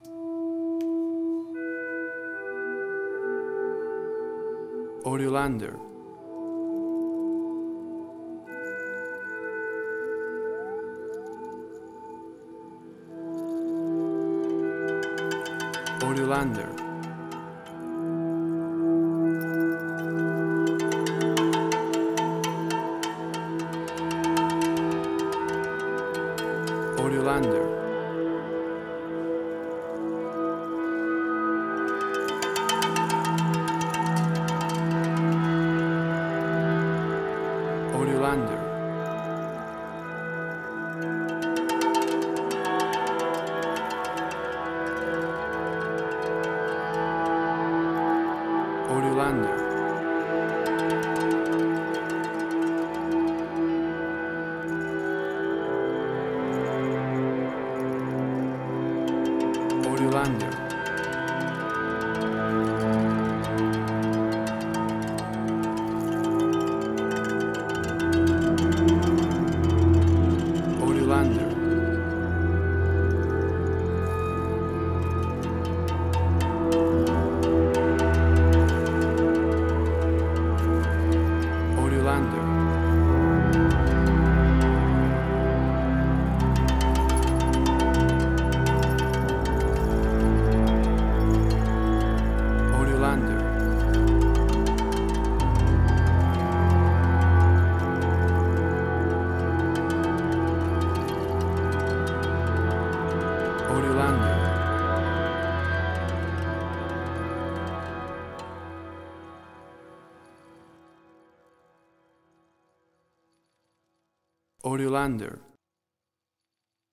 Asian Ambient.